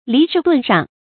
离世遁上 lí shì dùn shàng
离世遁上发音